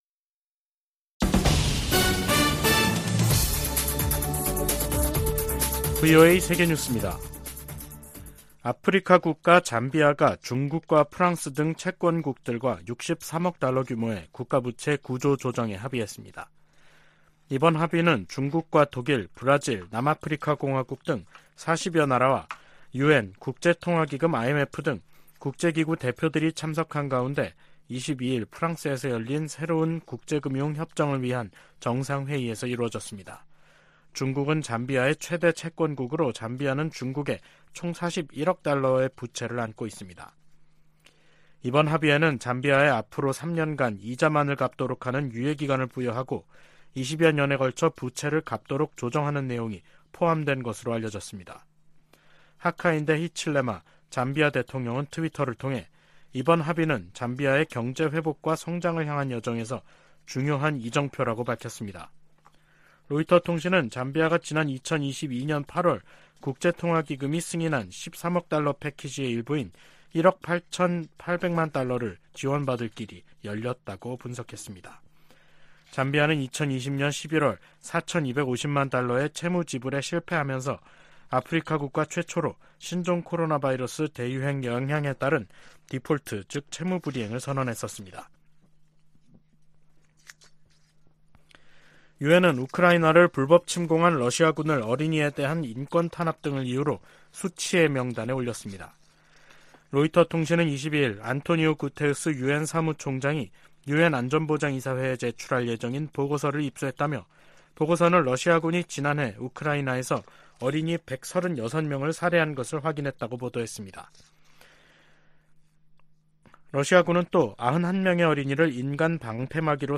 VOA 한국어 간판 뉴스 프로그램 '뉴스 투데이', 2023년 6월 23일 3부 방송입니다. 북한이 앞으로 몇 년동안 핵무기를 강압 외교에 활용할 가능성이 높다고 미국 정보당국이 전망했습니다. 조 바이든 미국 대통령과 나렌드라 모디 인도 총리가 북한의 미사일 발사를 규탄하고 한반도 비핵화 약속을 재확인했습니다. 한국 정부는 북한이 젊은 여성과 소녀를 내세워 체제 선전을 해 온 유튜브 채널들을 차단했습니다.